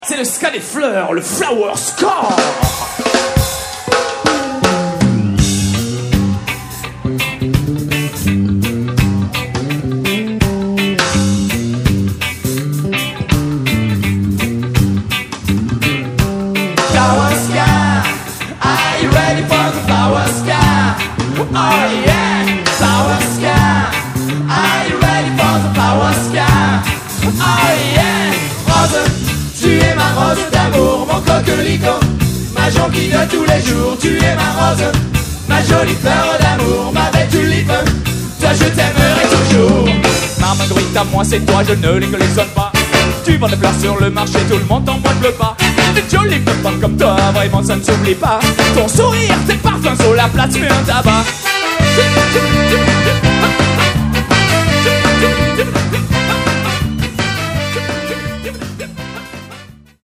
QUATRIÈME ALBUM (LIVE)